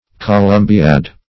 columbiad - definition of columbiad - synonyms, pronunciation, spelling from Free Dictionary
Columbiad \Co*lum"bi*ad\, n. [From Columbia the United States.]